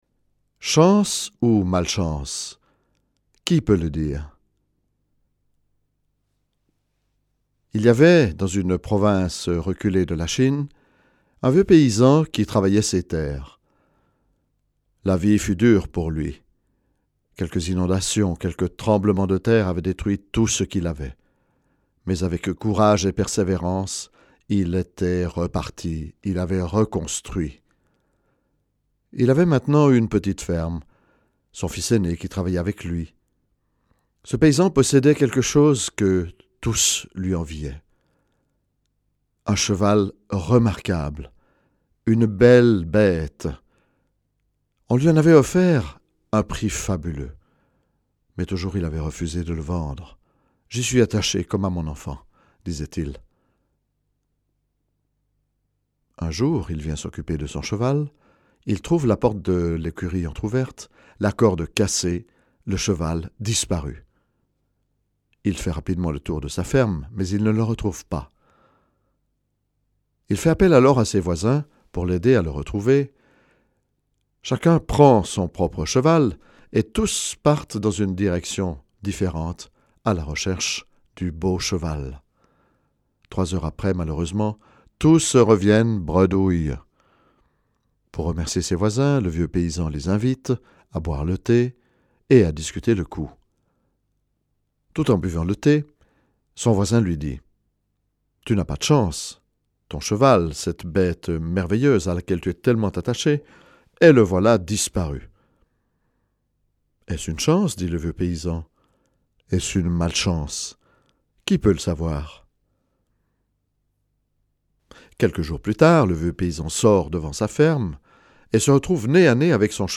Conte de sagesse taoïste.
Version AUDIO racontée